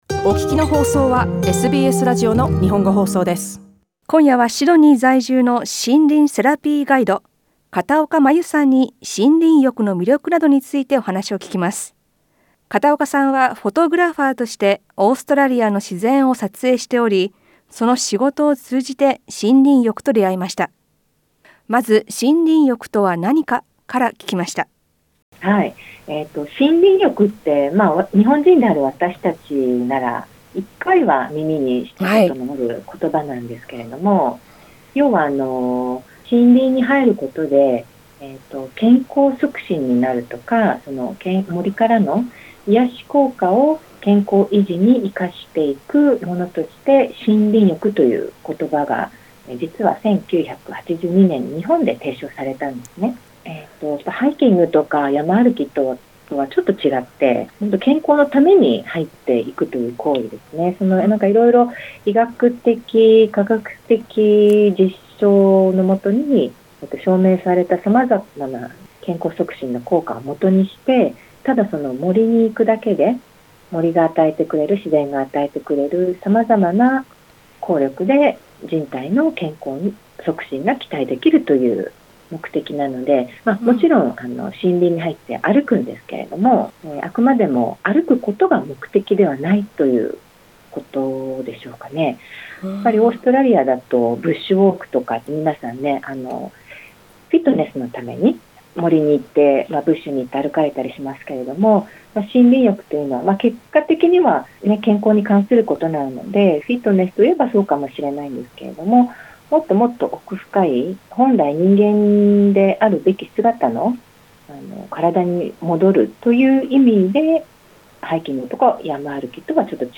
インタビューでは森林浴とは何か、そしてロックダウン中でも自然から癒やしを得る方法を聞きました。